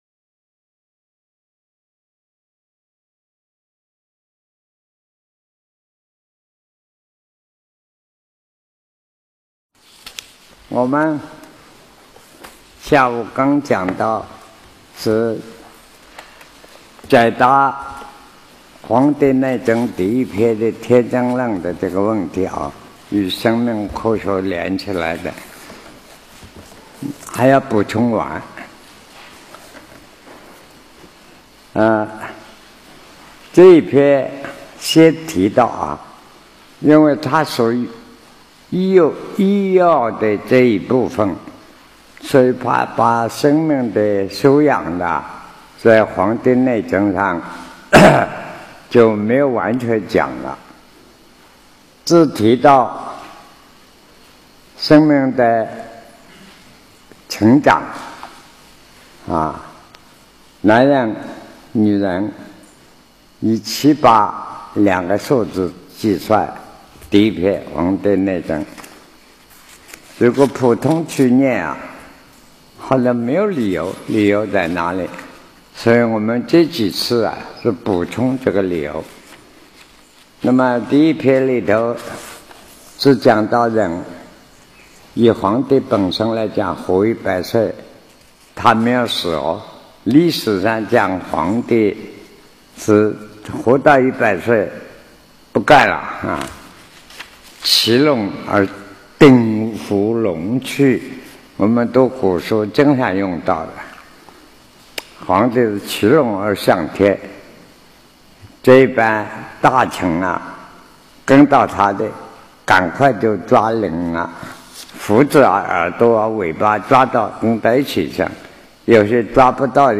南师讲《黄帝内经》05
南師講《黃帝內經》05.mp3